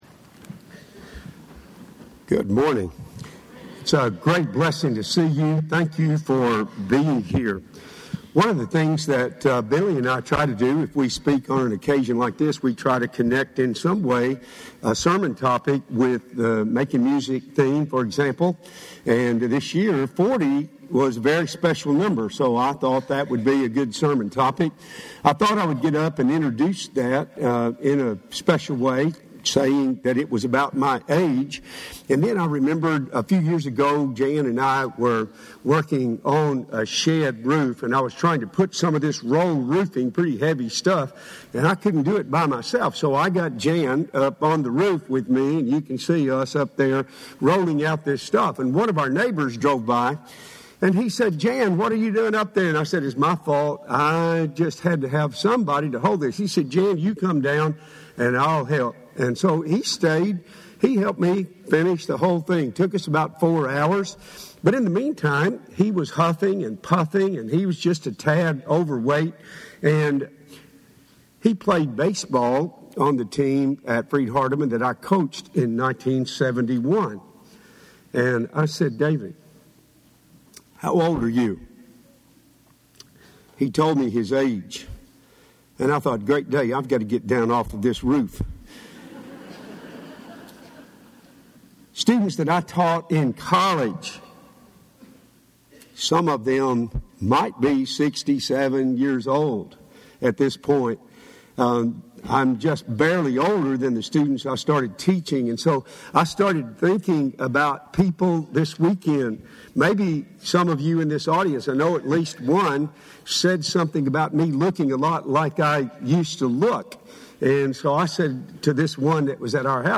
40 Is a Special Number – Henderson, TN Church of Christ